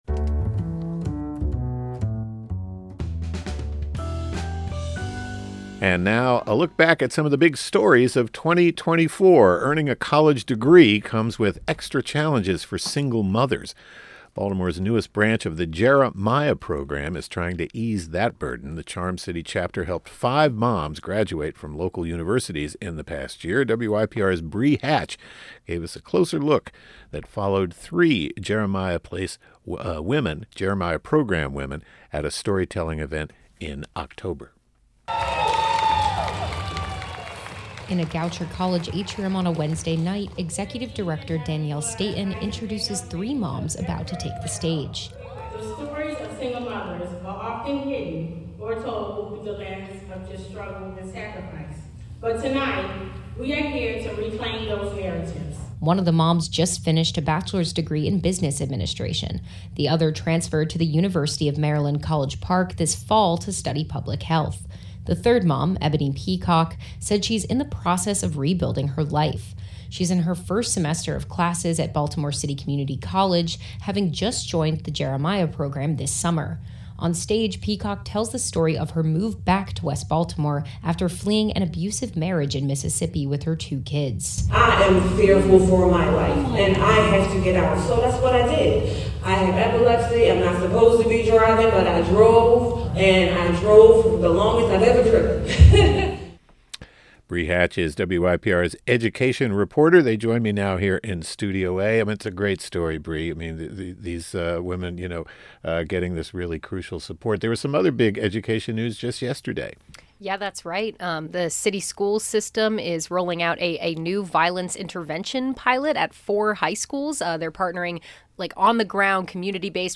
As 2024 draws to a close, we round up a busy year covering the headlines. WYPR reporters take us behind the microphone as they recount covering the stories that made 2024 unforgettable.